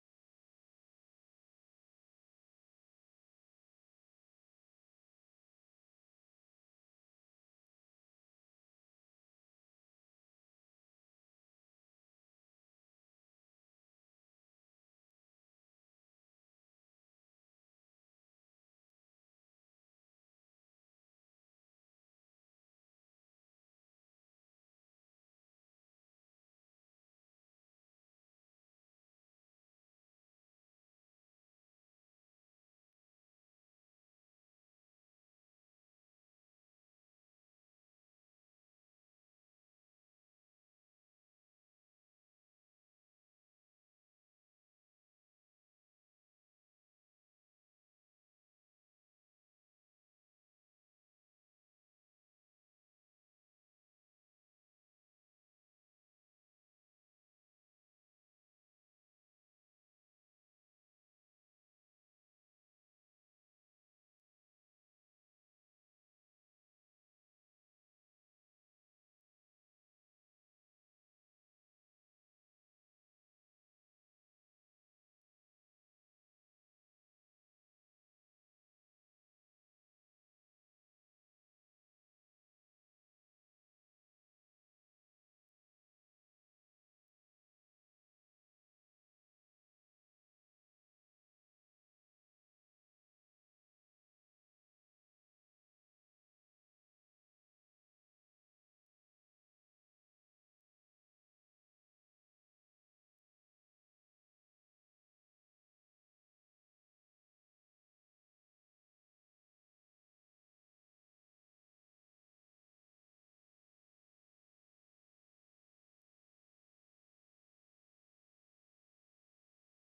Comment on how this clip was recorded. LIVE Evening Worship Service - A Potter's World